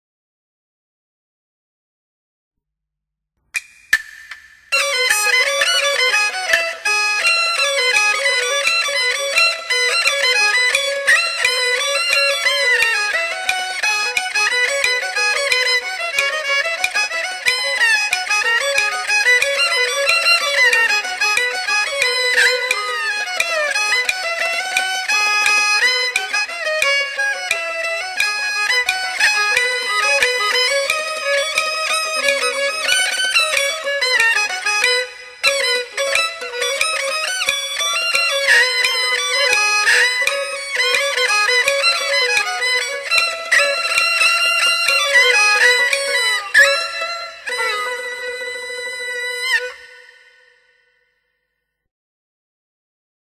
中國民族樂器經典欣賞系列